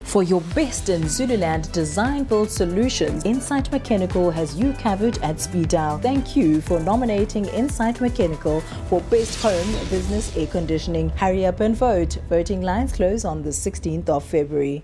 authentic, authoritative, soothing
Insight Mechanical advert demo